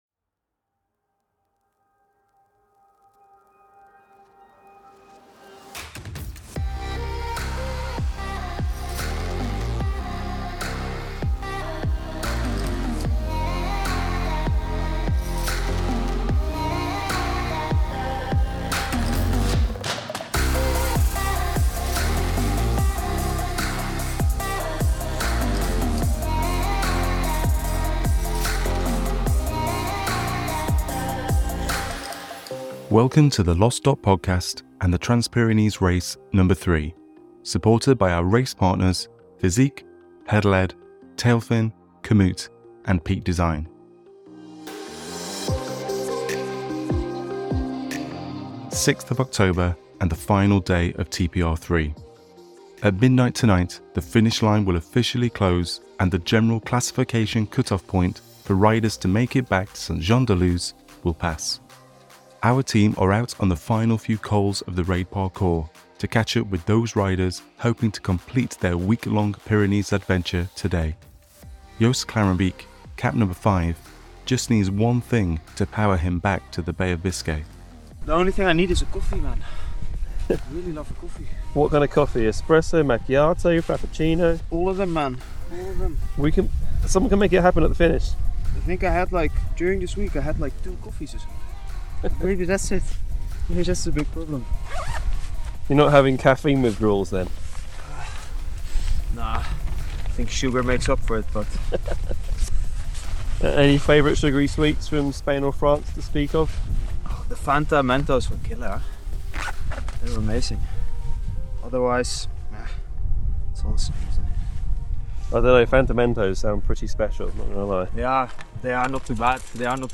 We present to you the final podcast to round out TPRNo3. Hear from riders in their final hours before the Finish, and stories from the last day on the road as the Raid delivers persevering cyclists back to the west coast and to the Finisher’s Party.